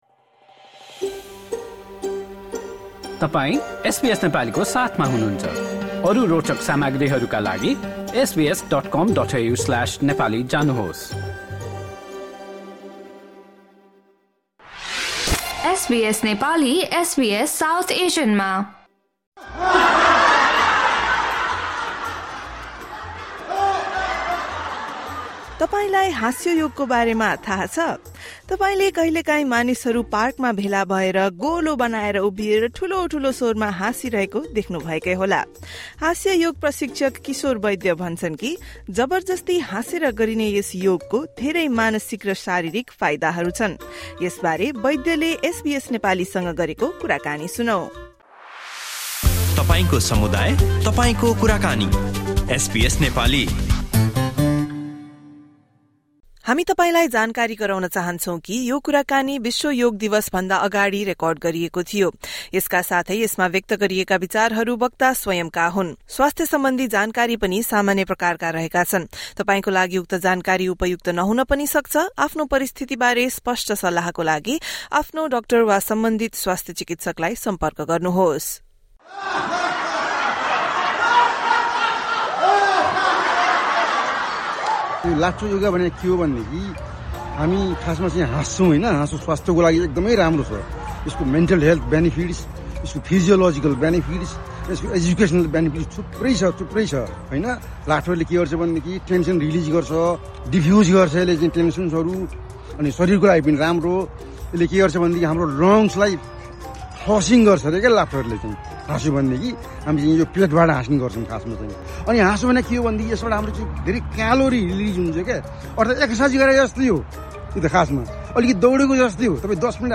Disclaimer: We would like to inform you that this interview was recorded before the World Yoga Day.